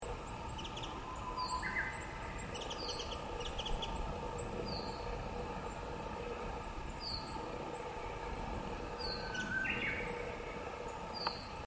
校庭西側の林の木々も新芽が萌えだしきれいです。小鳥たちの声も聞こえます。 小鳥のさえずり.mp3 （クリックしてみてください。ウグイスの声も聞こえます。）